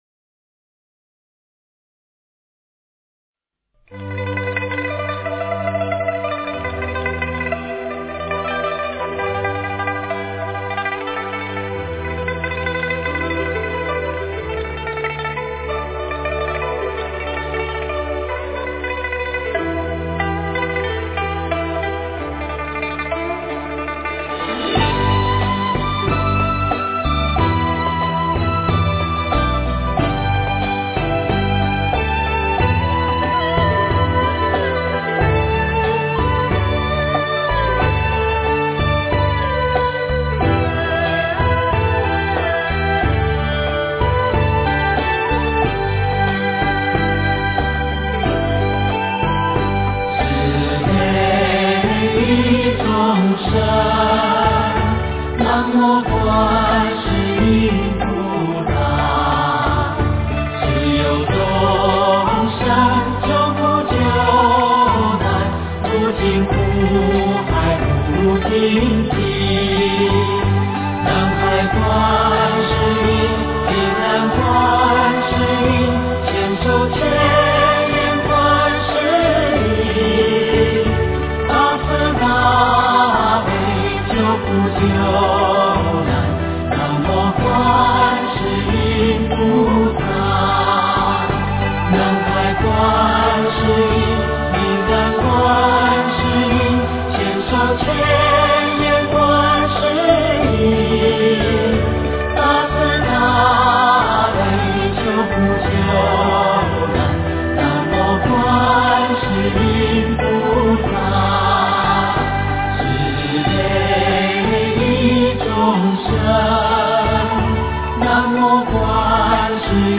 诵经
佛音 诵经 佛教音乐 返回列表 上一篇： 南无本师释迦牟尼佛 下一篇： 般若波罗蜜多心经 相关文章 53.宗教徒的心胸--佚名 53.宗教徒的心胸--佚名...